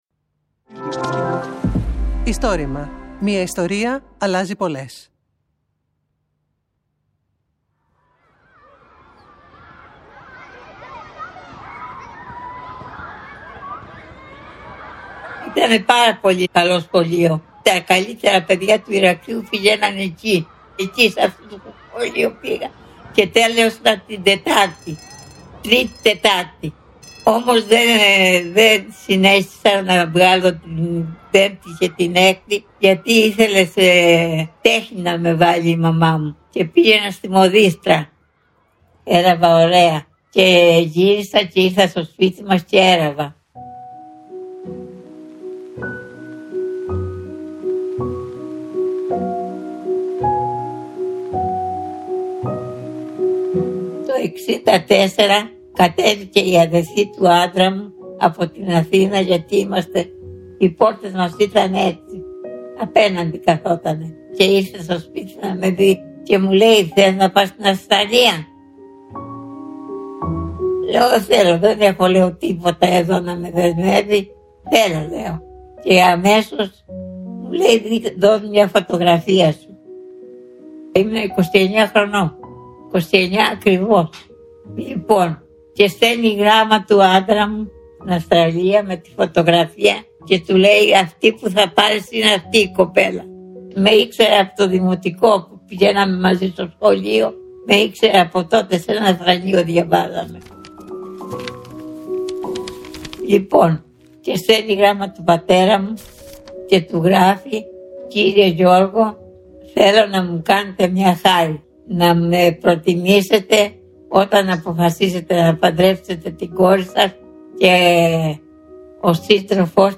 Ήταν κι αυτό ένα από τα προξενιά με μια φωτογραφία. Το μόνο που ήθελε, ήταν να μπει στο πλοίο και να πάει να τον συναντήσει… Αφηγήτρια
Το Istorima είναι το μεγαλύτερο έργο καταγραφής και διάσωσης προφορικών ιστοριών της Ελλάδας.